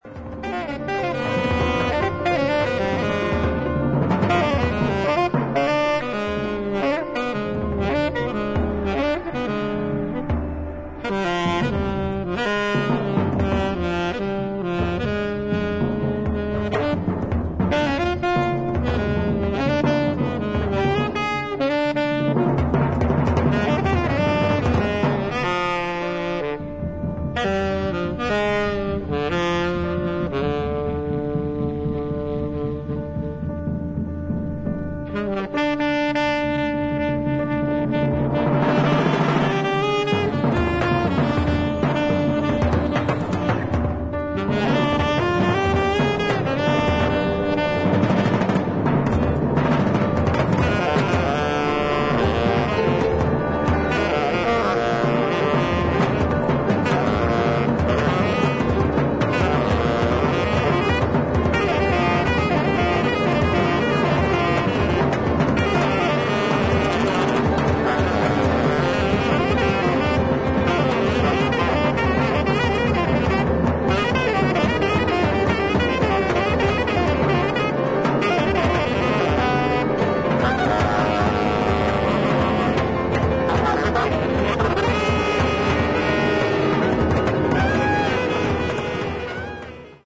1977年12月にイタリア/ピストイアで行ったライヴを収録したCDアルバム